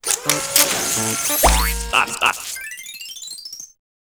dronebuild.wav